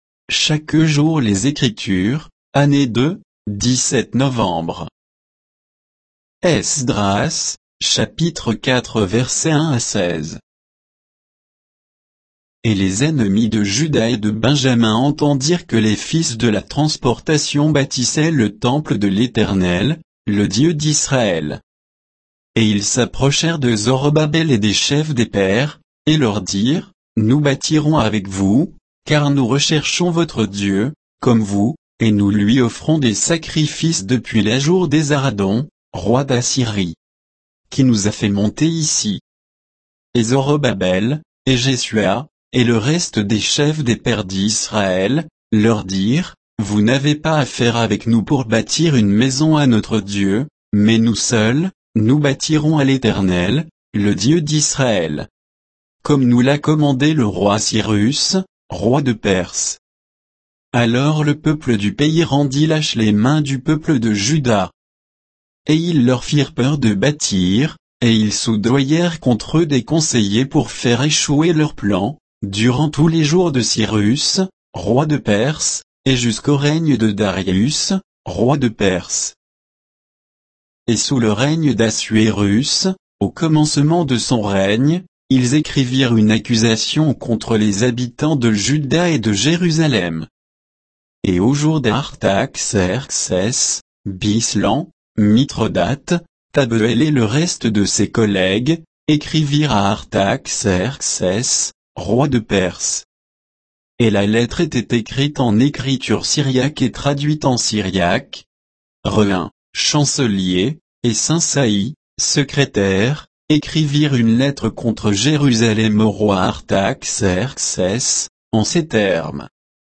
Méditation quoditienne de Chaque jour les Écritures sur Esdras 4, 1 à 16